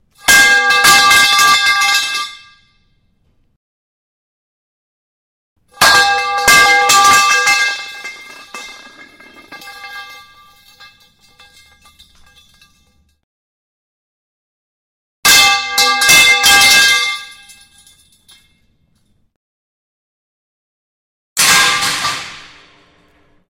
На этой странице собраны разнообразные звуки железной палки: от резких звонких ударов до протяжных вибраций.
Звонкий удар металла тяжелого предмета